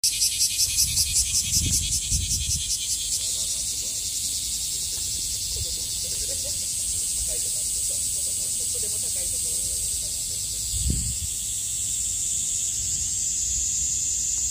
「クマゼミ」音声をいれました。長松小で今朝ないていた音声です。
せみ.mp3